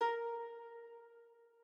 harp1_3.ogg